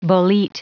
dicas de inglês, como pronunciar bolete em ingles (também boletus) significa boleto (gênero de cogumelo com várias espécies venenosas ou comestíveis.